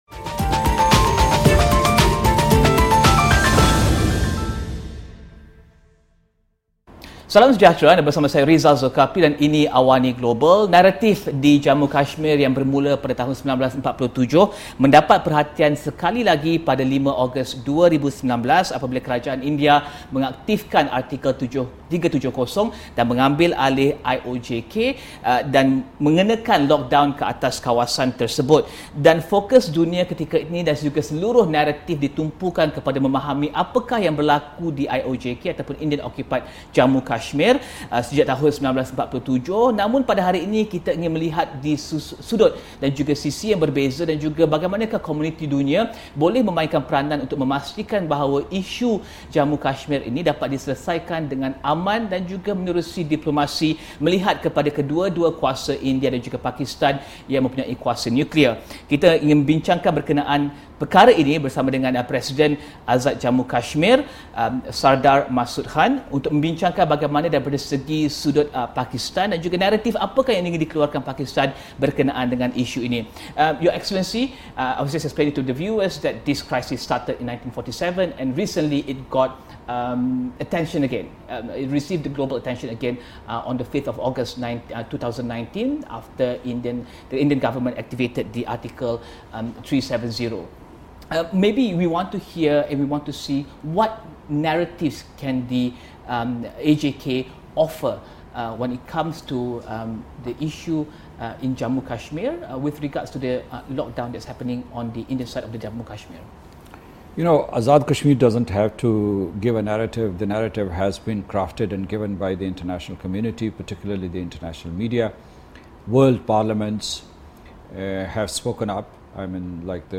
temubual bersama Presiden Sardar Masood Khan